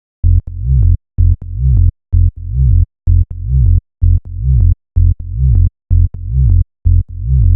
VDE 127BPM Rebound Bass Root F SC.wav